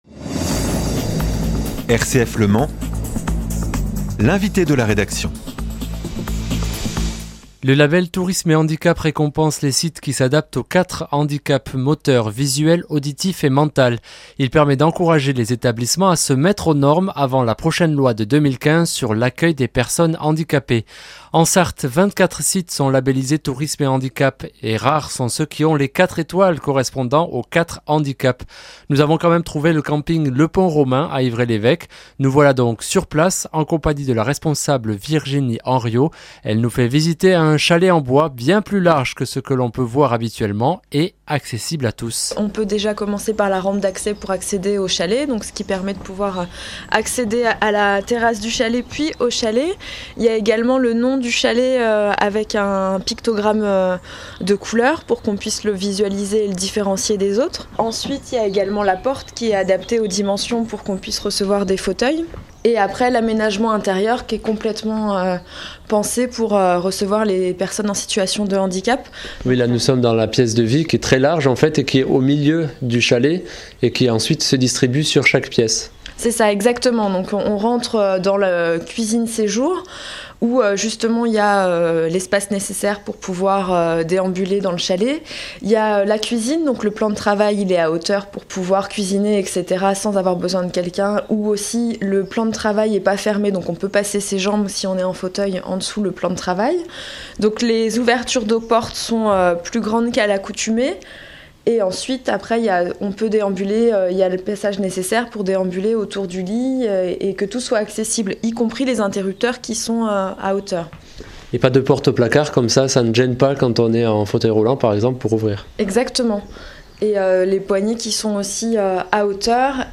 « La vie hors des boîtes » -Feuilleton radiophonique sur Prun’.